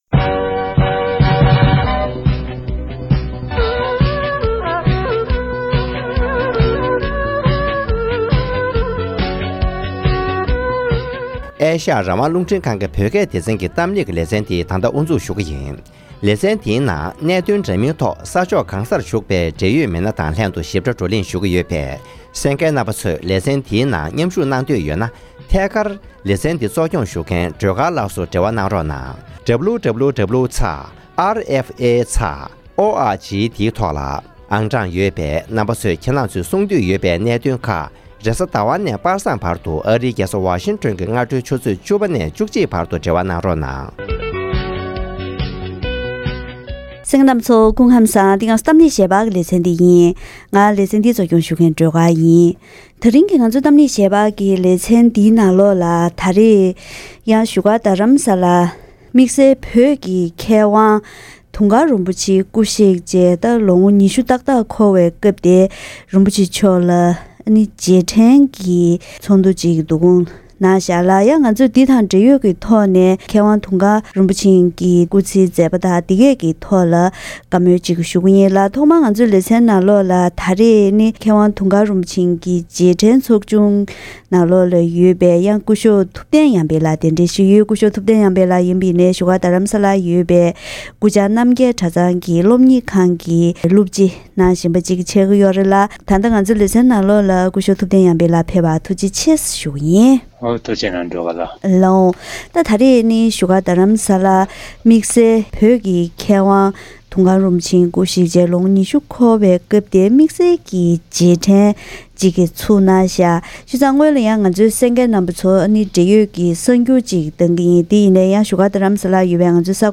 བཀའ་མོལ་ཞུས་པ་ཞིག་གསན་རོགས་གནང་།